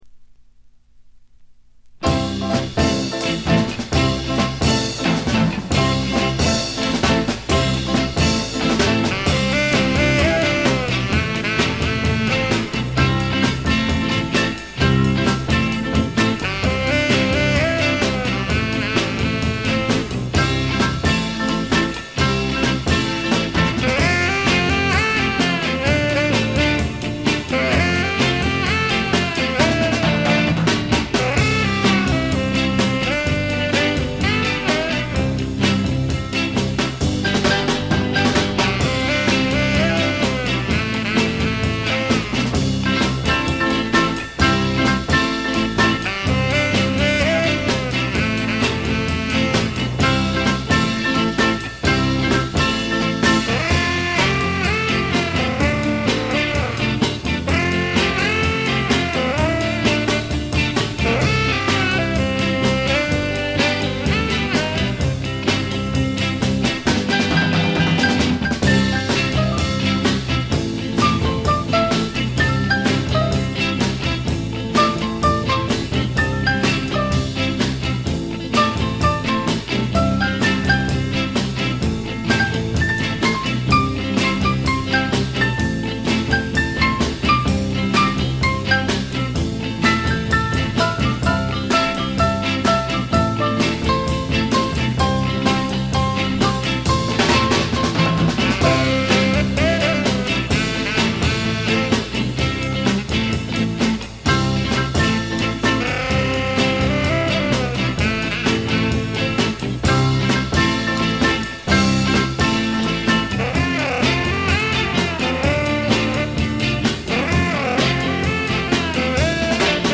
わずかなノイズ感のみ。
ぶりぶりで楽しいインスト集。
試聴曲は現品からの取り込み音源です。